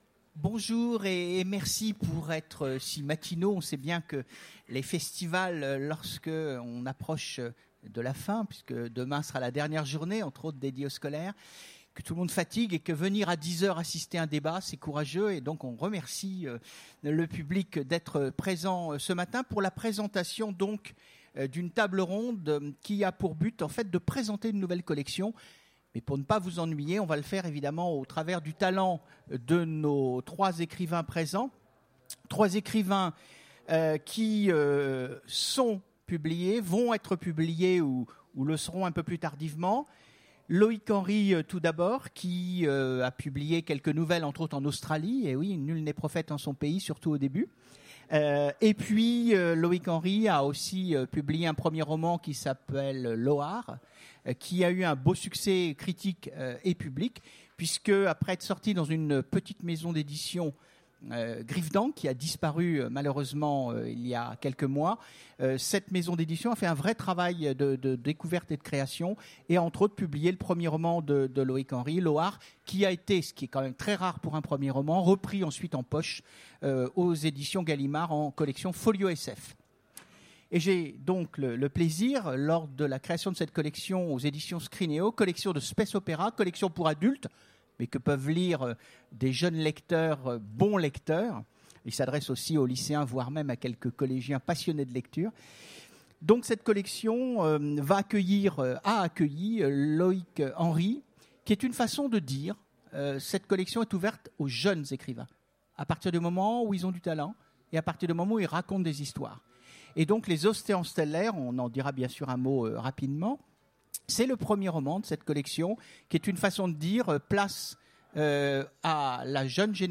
Utopiales 2016 : Conférence Space opera, une nouvelle collection chez Scrineo
Rencontre avec une maison d'édition